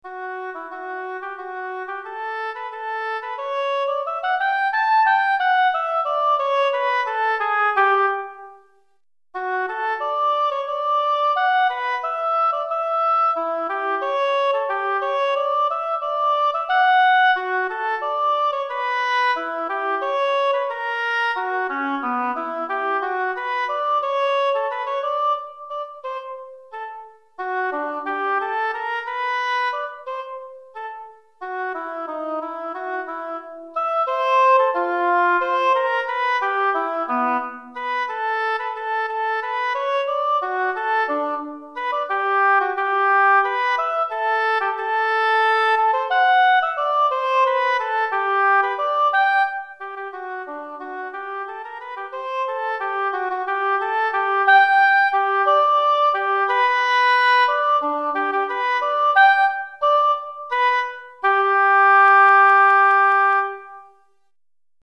Etude pour Hautbois - Hautbois Solo